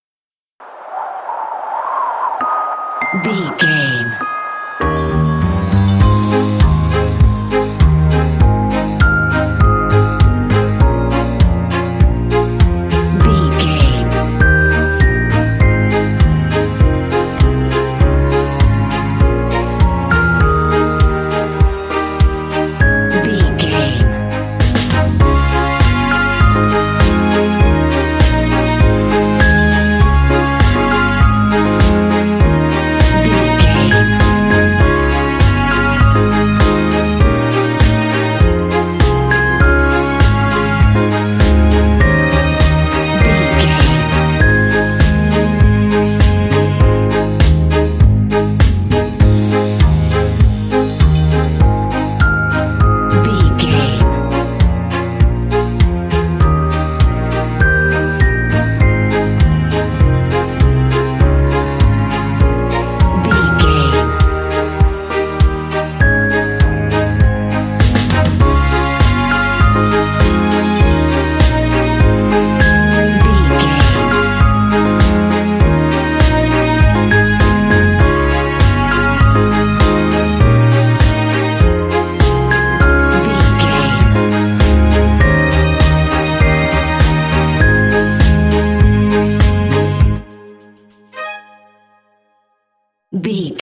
Ionian/Major
A♭
Fast
festive
hopeful
soft
epic
dreamy
piano
strings
drums
percussion